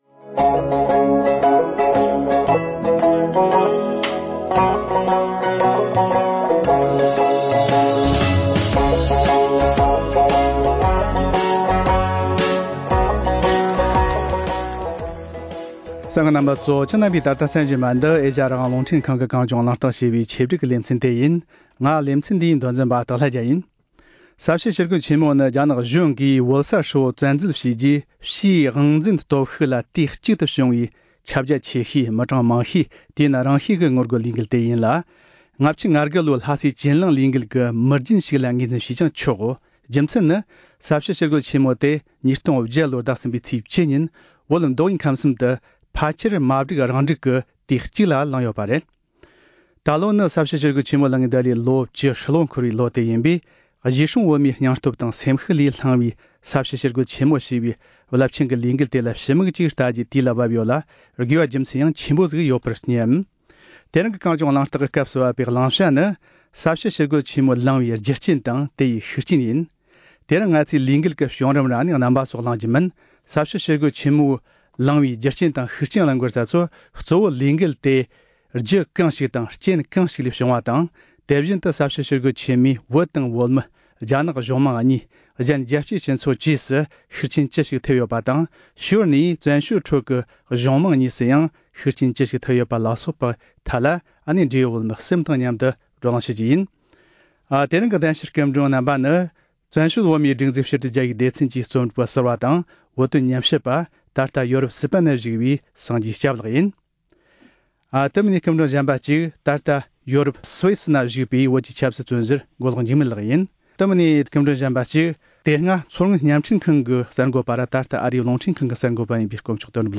ས་བྱི་ཞི་རྒོལ་ཆེན་མོ་ལངས་དགོས་པའི་རྒྱུ་རྐྱེན་དང་ལས་འགུལ་དེས་རྒྱལ་སྤྱི་དང་བཙན་བྱོལ་ཁྲོད་ཀྱི་གཞུང་མང་གཉིས་ལ་ཤུགས་རྐྱེན་གང་འདྲ་ཐེབས་ཡོད་པ་སོགས་ཀྱི་ཐད་བགྲོ་གླེང་།